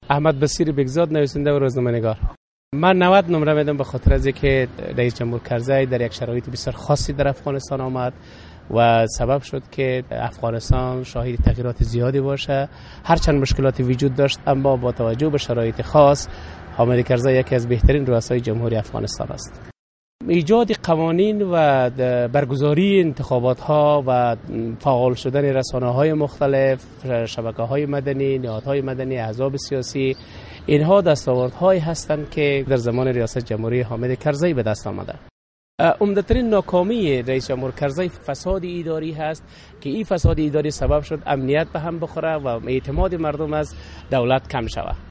The URL has been copied to your clipboard No media source currently available 0:00 0:00:45 0:00 لینک دانلود | ام‌پی ۳ View on Hamid Karzai Legacy برای شنیدن مصاحبه در صفحۀ جداگانه اینجا کلیک کنید